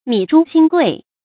米珠薪桂 mǐ zhū xīn guì 成语解释 米贵得象珍珠，柴贵得象桂木。